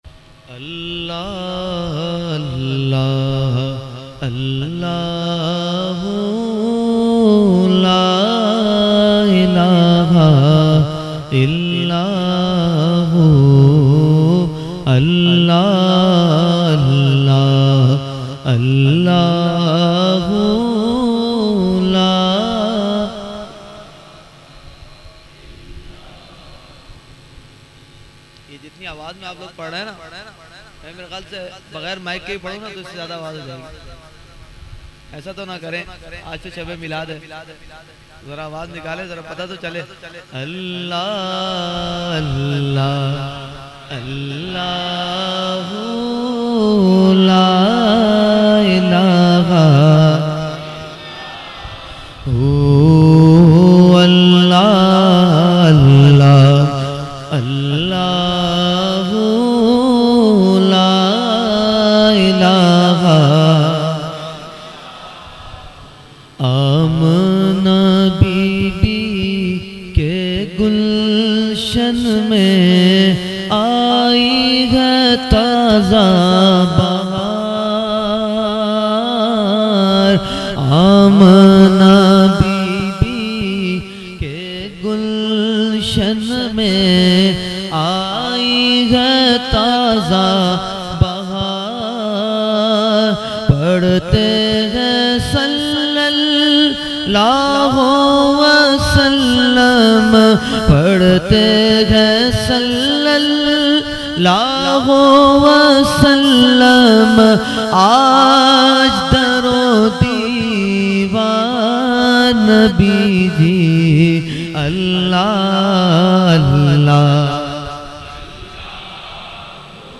Mehfil e Jashne Subhe Baharan held on 28 September 2023 at Dargah Alia Ashrafia Ashrafabad Firdous Colony Gulbahar Karachi.
Category : Naat | Language : UrduEvent : Jashne Subah Baharan 2023